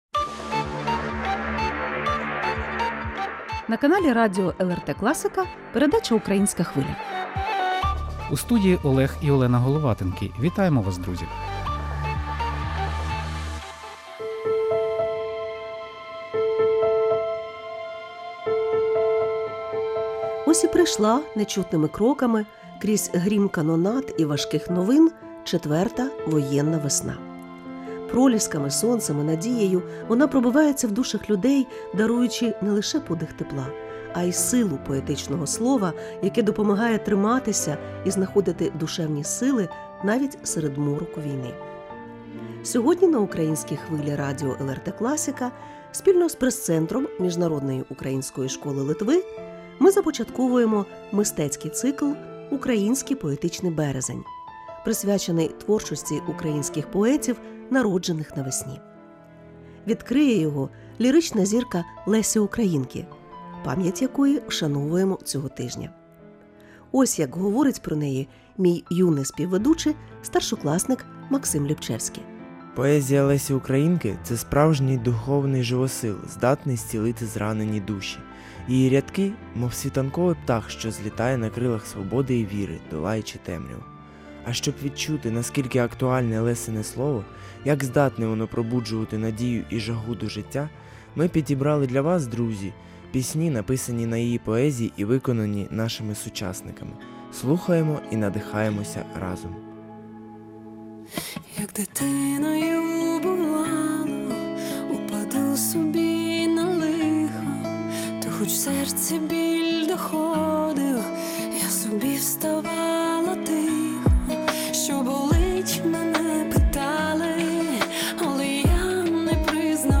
У передачі звучать геніальні твори поетеси, покладені на музику і заспівані нашими сучасниками.